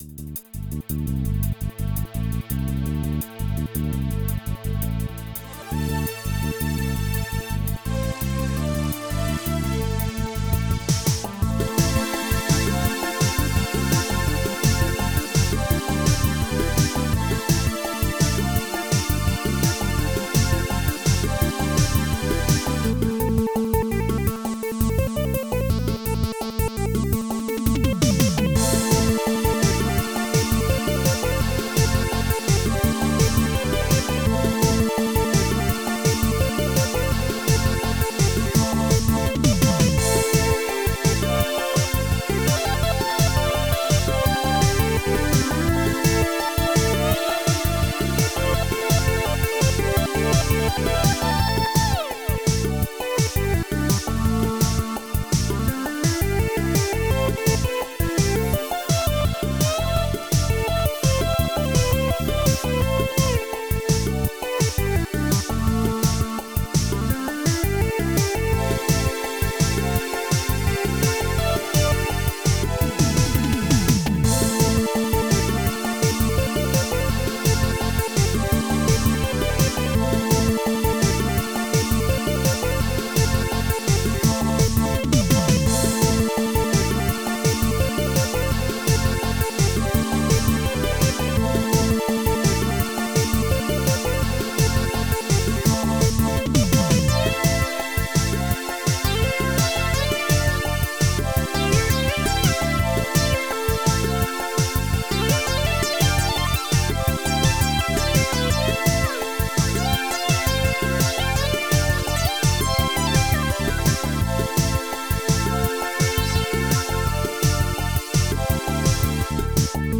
Extended Module
xm (FastTracker 2 v1.04)